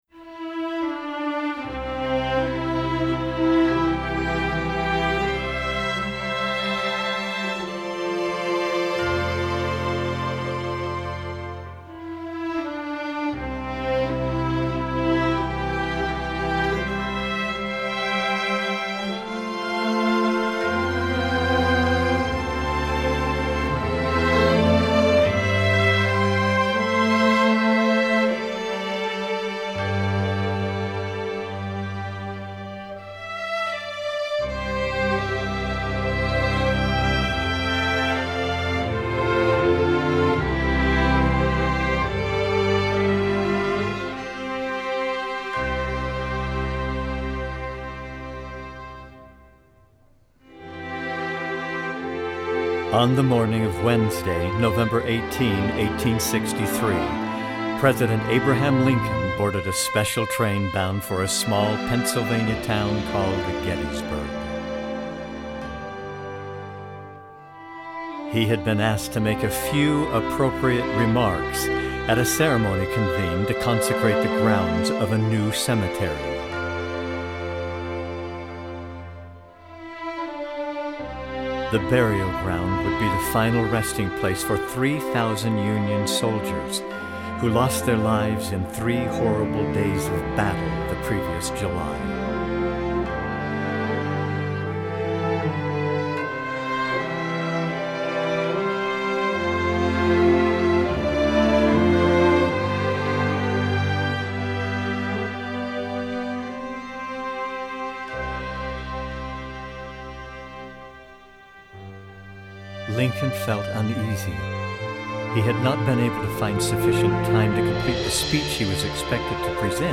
Instrumentation: string orchestra
instructional
Narrator part:
Chimes part:
Cymbals part:
Snare drum part: